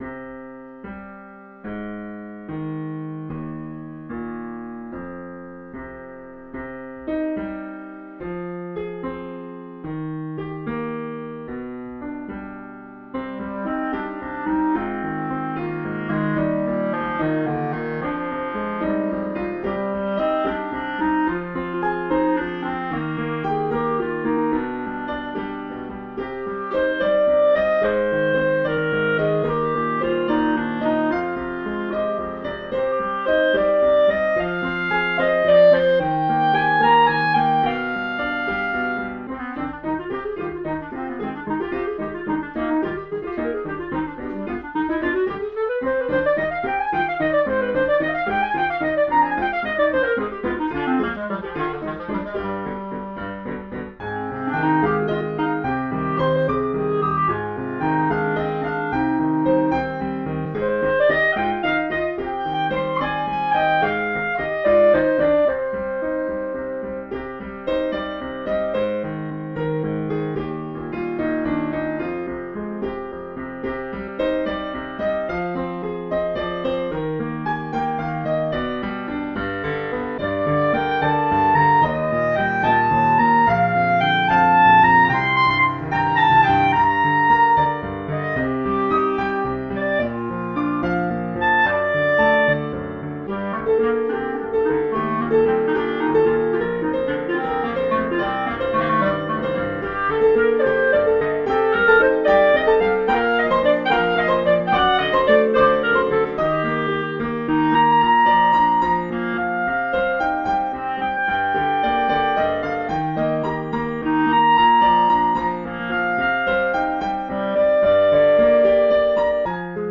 Formule instrumentale : Clarinette et piano
Oeuvre pour clarinette et piano.